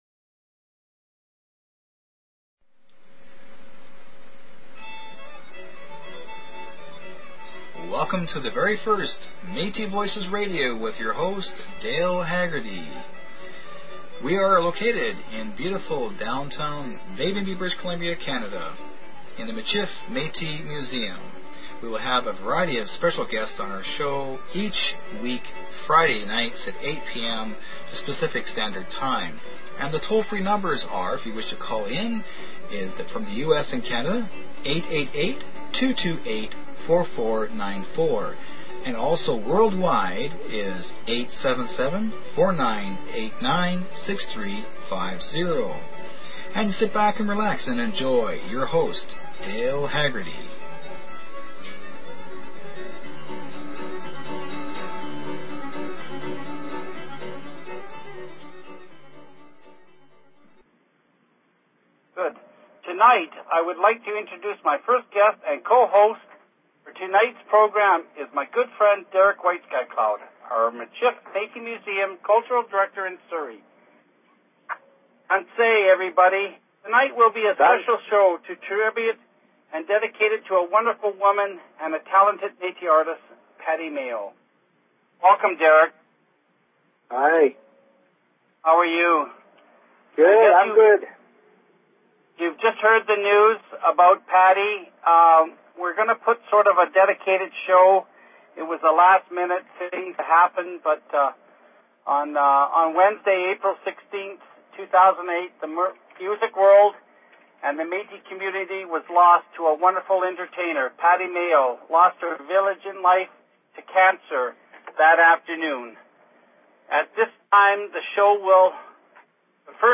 Talk Show Episode, Audio Podcast, Metis_Voices_Radio and Courtesy of BBS Radio on , show guests , about , categorized as